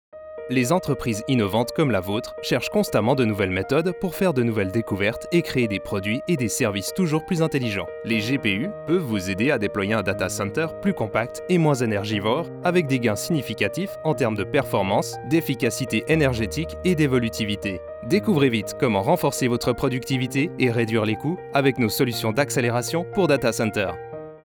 From my home studio I will deliver a professional, clean voice over track.
Sprechprobe: Industrie (Muttersprache):
Corporate_2_FR_WithMusic.mp3